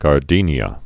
(gär-dēnyə)